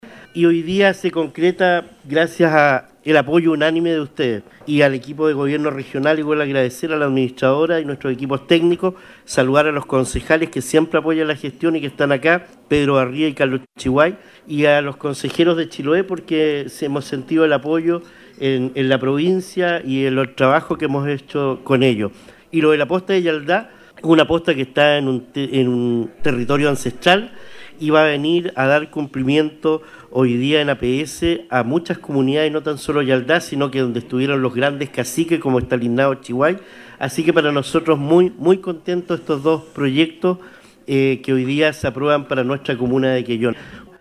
El Alcalde de Quellón, Cristian Ojeda, agradeció la buena disposición de todos los consejeros regionales y principalmente de los consejeros de Chiloé, que siempre han apoyado iniciativas impulsadas desde el municipio quellonino: